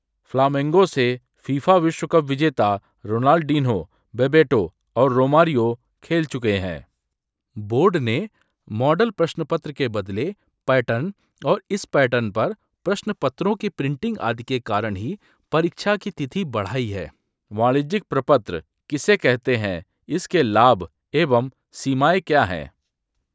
TTS_multilingual_audios